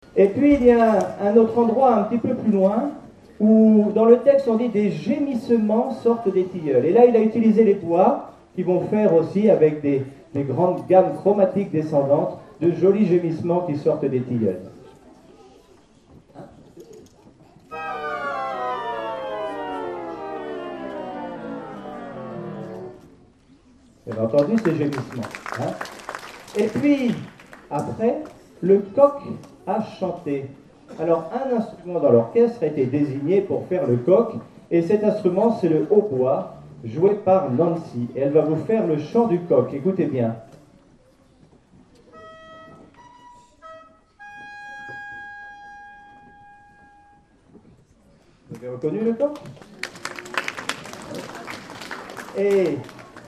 Album: Concert pédagogique 2011